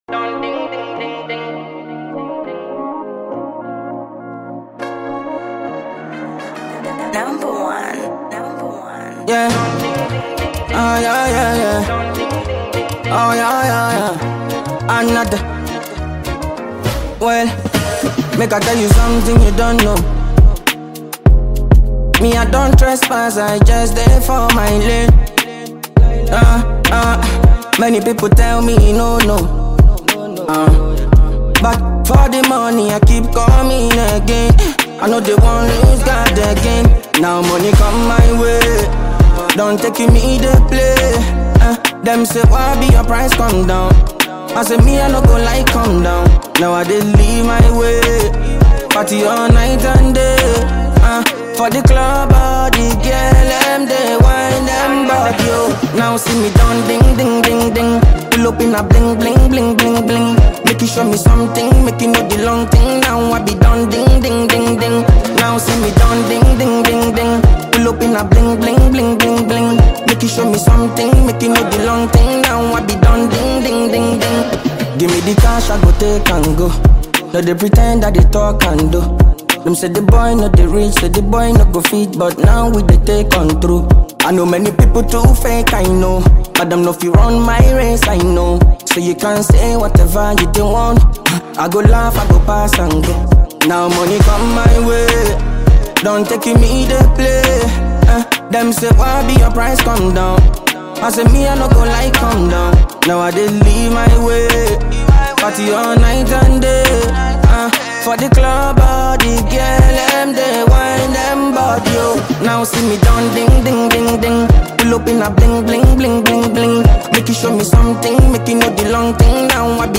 Ghanaian Afrobeat badman